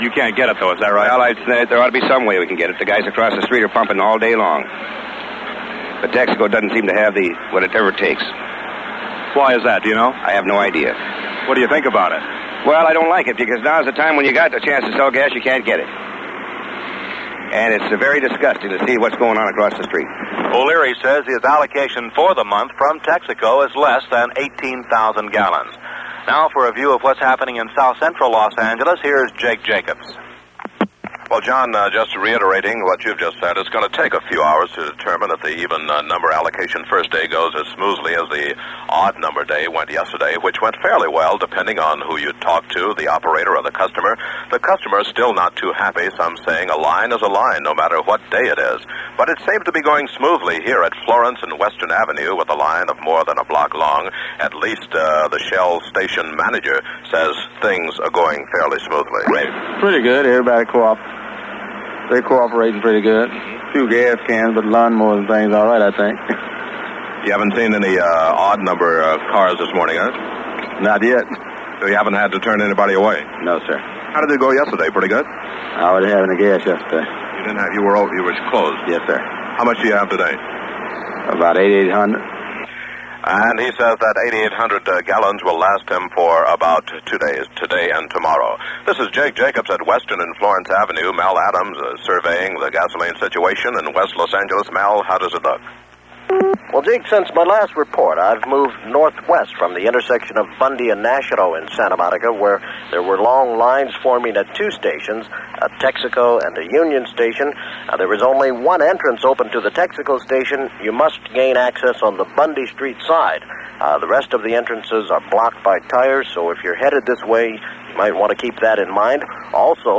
News-for-May-10-1979.mp3